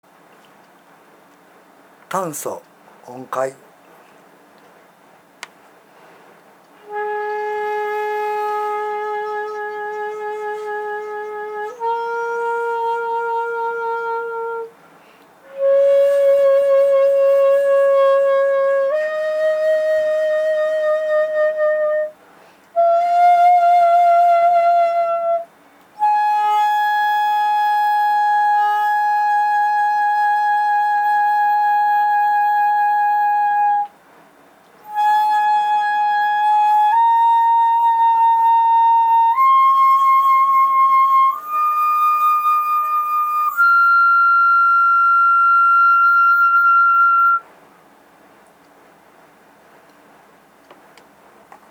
今度は短簫（タンソ）の音階図を音を聴きながらごらんください。
（短簫の音階音源）
一節切も短簫もA（ラ）を主音とした雅楽で言う律音階を使っています。